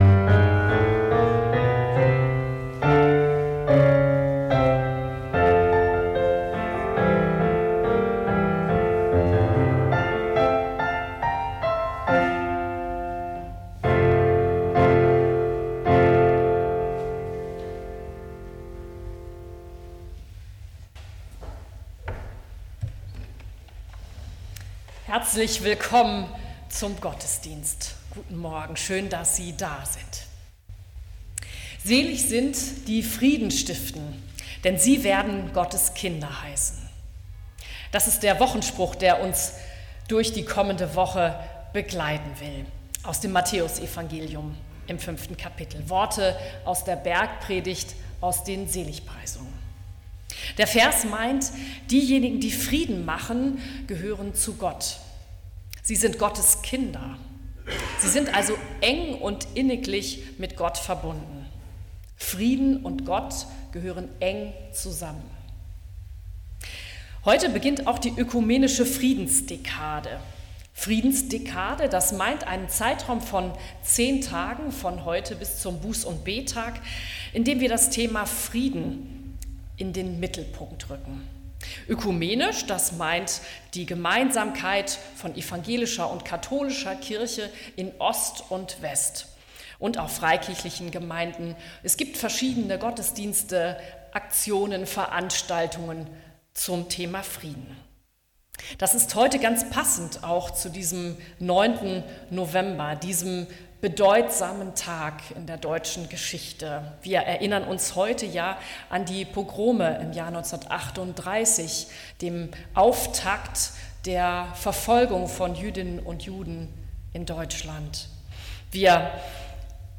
Gottesdienst - 09.11.2025 ~ Peter und Paul Gottesdienst-Podcast Podcast